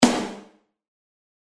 Вложения snare.mp3 snare.mp3 29,6 KB · Просмотры: 221